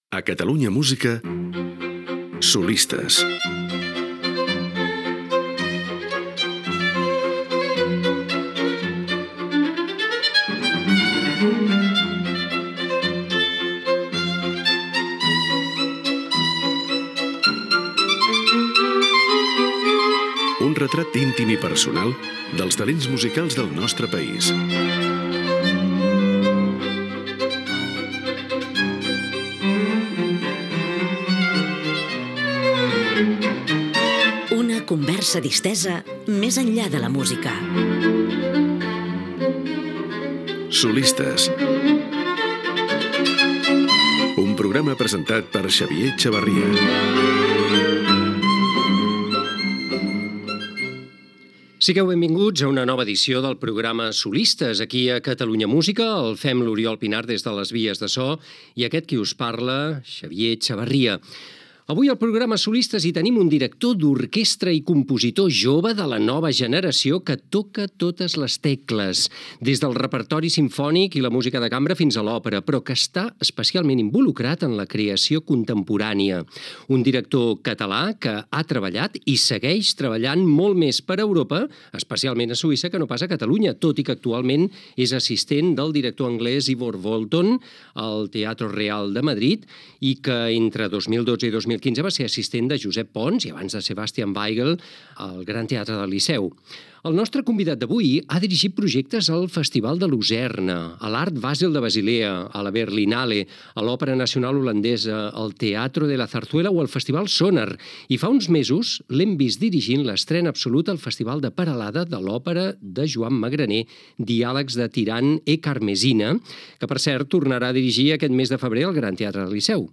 Musical
FM